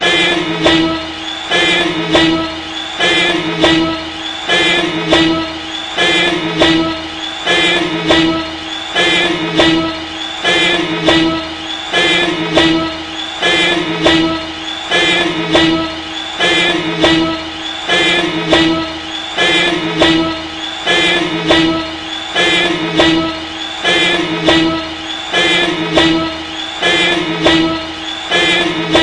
Genres: Country (9)